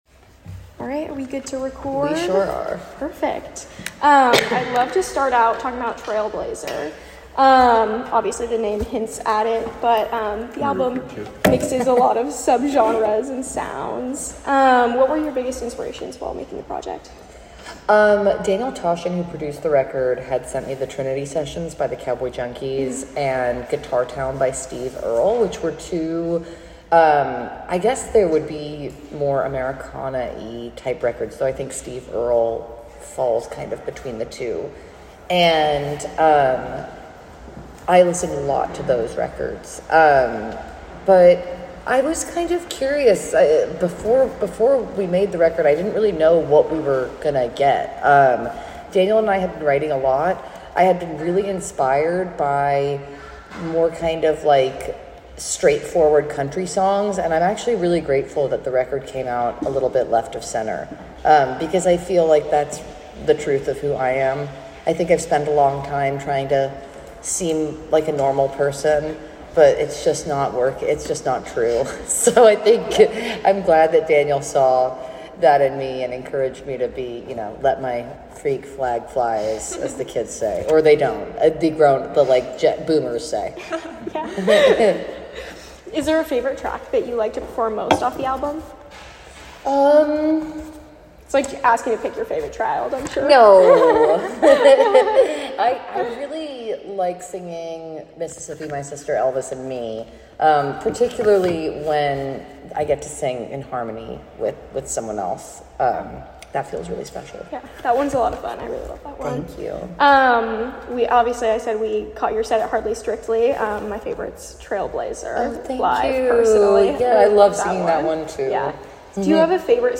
Last Tuesday, as the rain clouds rolled back in over San Francisco, I was joined by the lovely and talented Lola Kirke, before her show at the Swedish American Hall on the tail end of The TMI tour.
Lola-Kirke-Blog_Interview.mp3